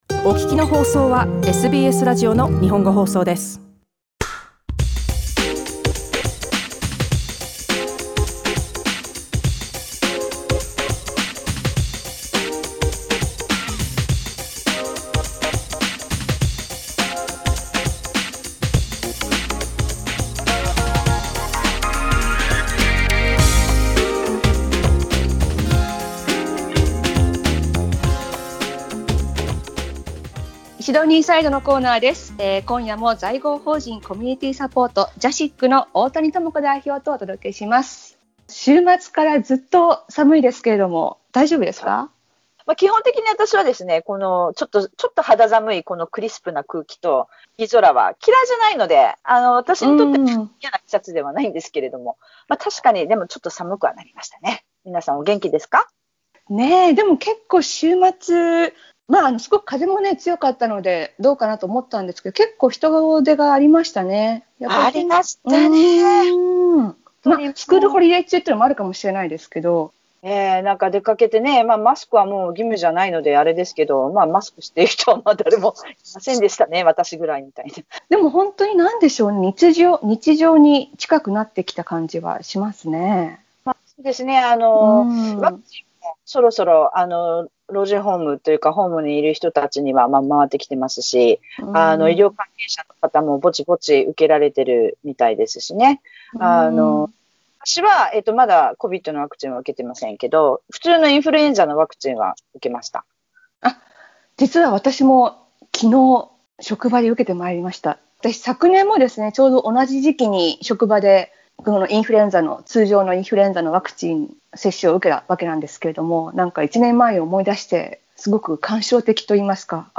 火曜日のラジオ番組でイベント情報やコミュニティー情報を伝えるコーナーです。